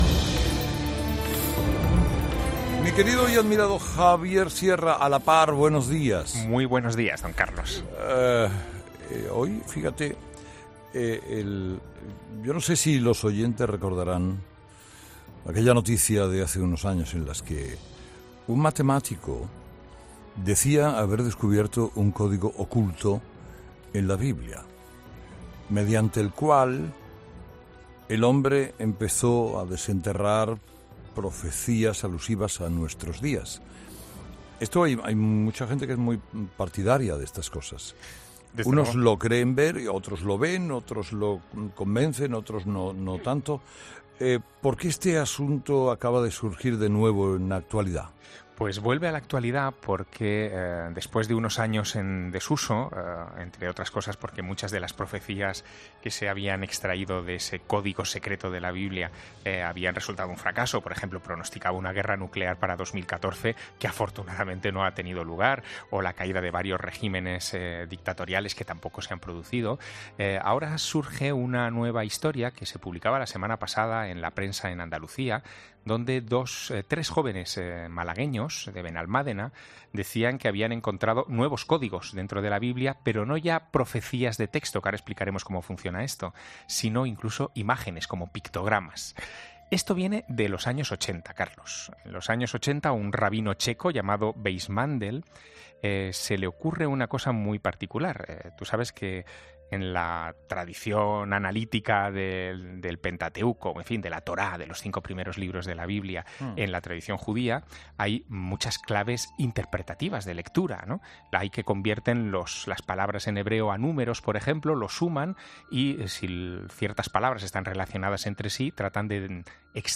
Escucha ahora la sección de Javier Sierra, emitida el martes 21 de mayo de 2019, en 'Herrera en COPE'.